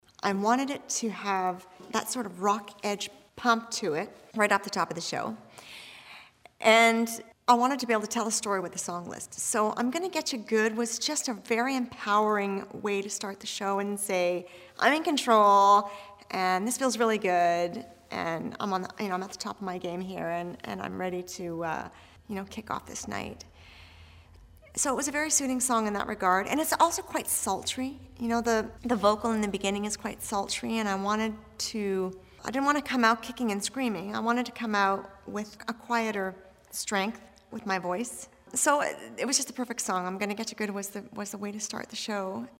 Audio / Shania Twain talks about opening her Las Vegas Show with “I’m Gonna Getcha Good!”